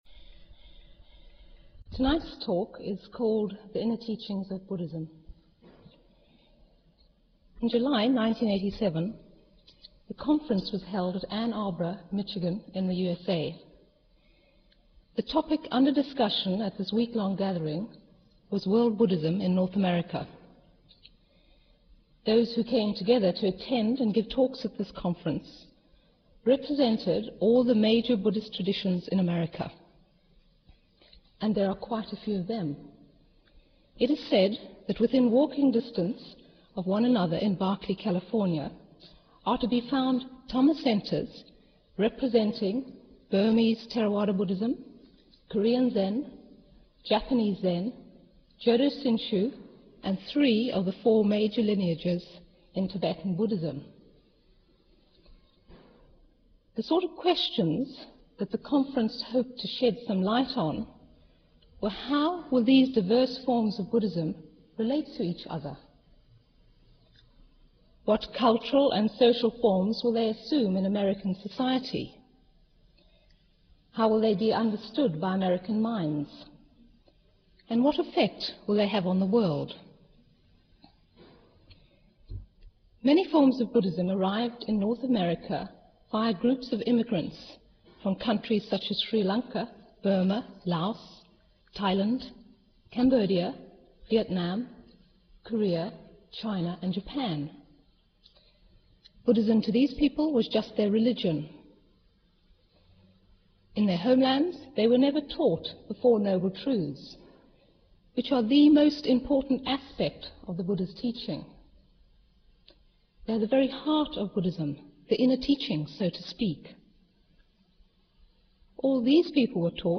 This talk was given in April 1988